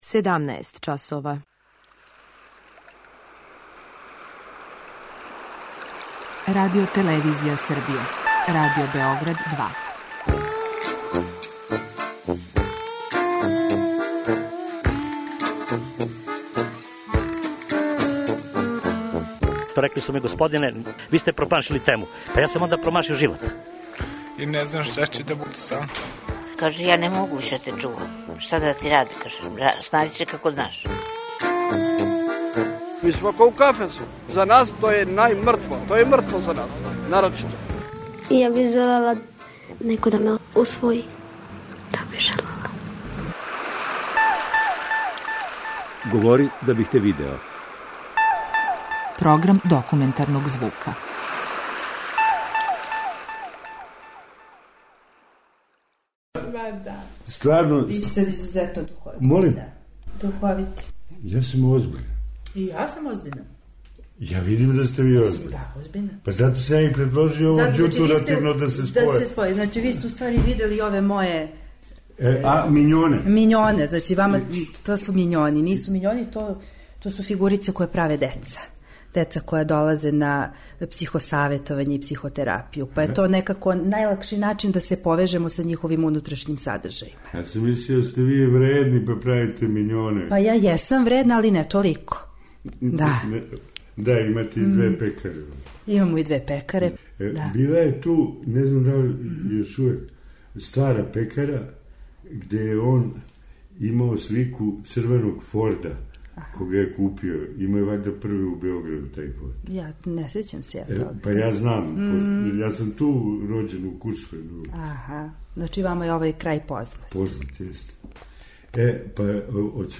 Документарни програм
преузми : 10.72 MB Говори да бих те видео Autor: Група аутора Серија полусатних документарних репортажа, за чији је скупни назив узета позната Сократова изрека: "Говори да бих те видео". Ова оригинална продукција Радио Београда 2 сједињује квалитете актуелног друштвеног ангажмана и култивисане радиофонске обраде.